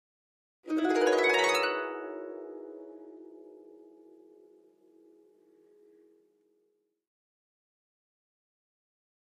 Harp, Thin, Short And High Strings, Gliss, Type 2